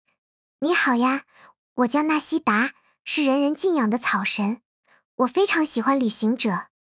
Springboot集成AI Springboot3 集成阿里云百炼大模型CosyVoice2 实现Ai克隆语音(未持久化存储)